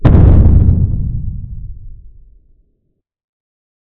explosion4.ogg